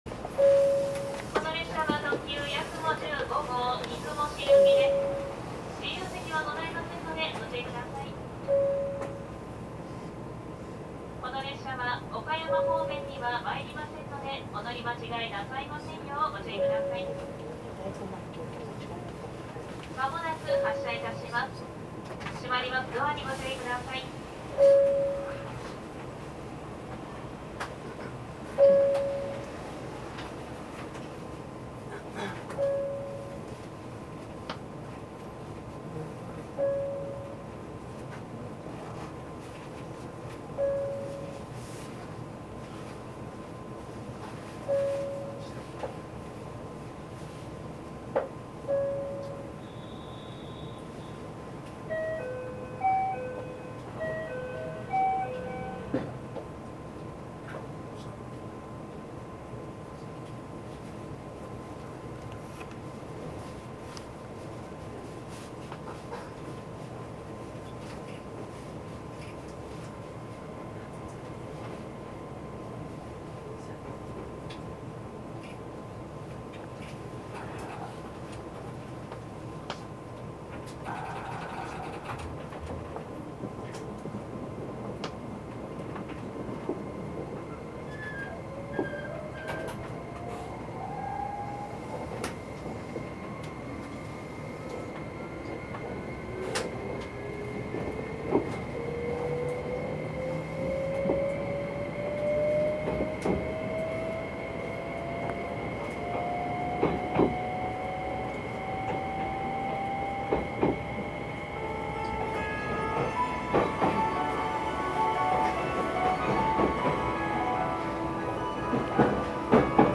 走行音
録音区間：米子～安来(やくも15号)(お持ち帰り)